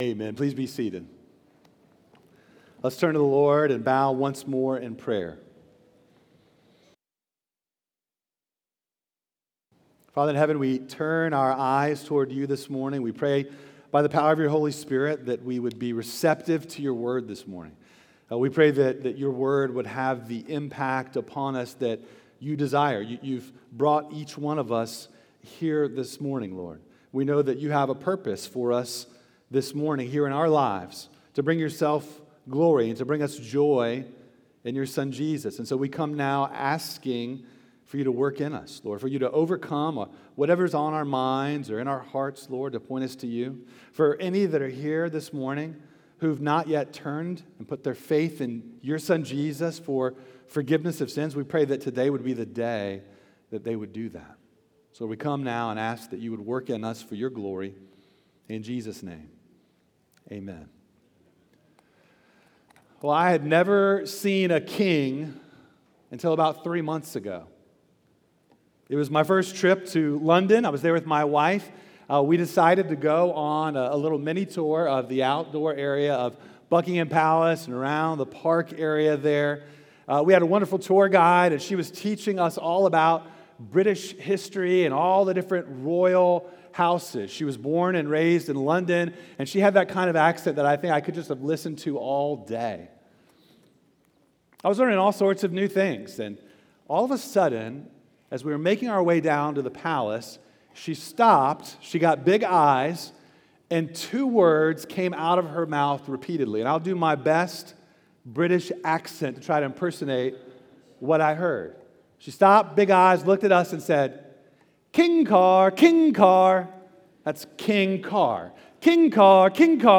Sermons by Oakhurst Baptist Church Sermons